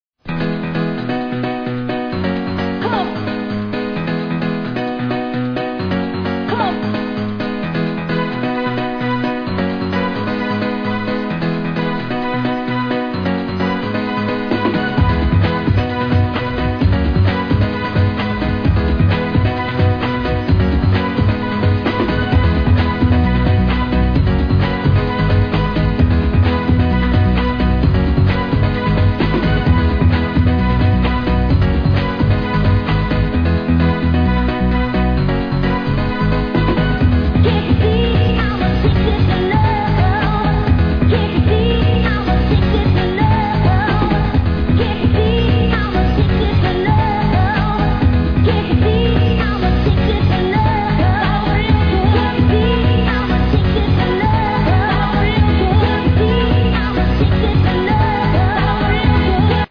Proper old skool c1990/91 tune to be ID'no.1!